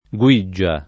guiggia [ gU&JJ a ] s. f.; pl. -ge